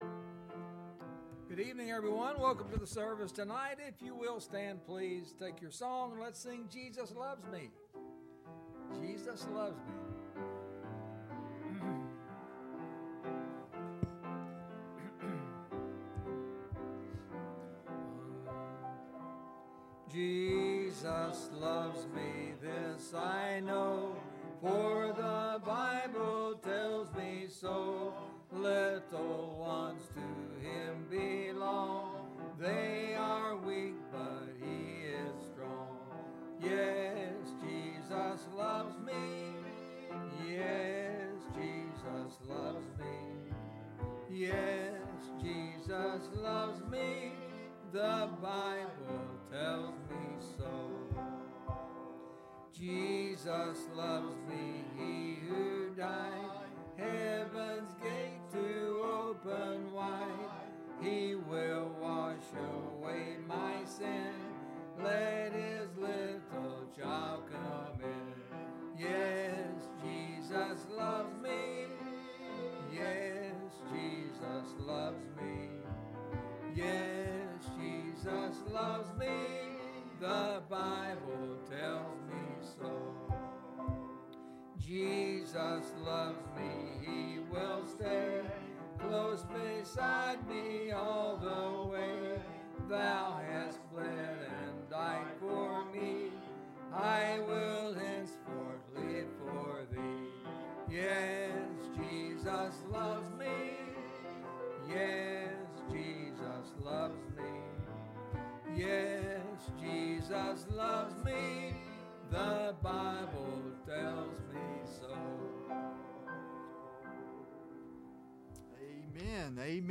Wednesday Evening Service - December 29